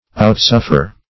Outsuffer \Out*suf"fer\, v. t. To exceed in suffering.